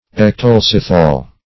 Search Result for " ectolecithal" : The Collaborative International Dictionary of English v.0.48: Ectolecithal \Ec`to*lec"i*thal\, a. [Ecto- + Gr.
ectolecithal.mp3